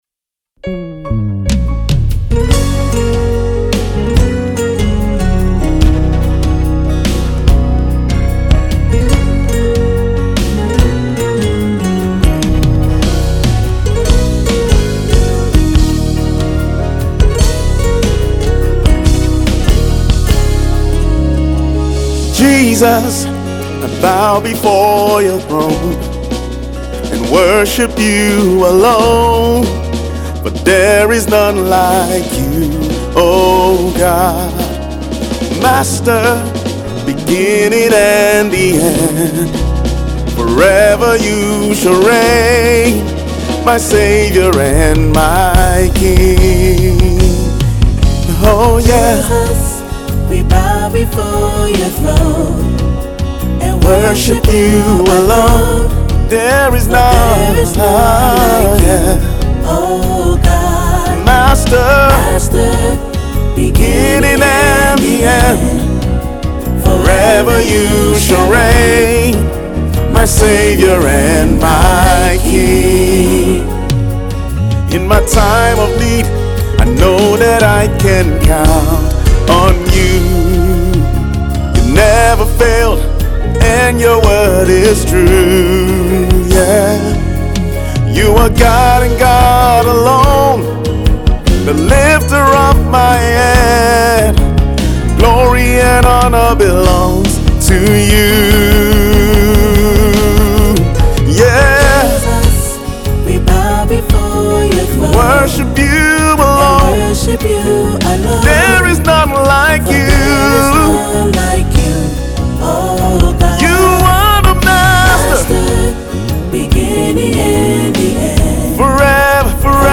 uptempo celebration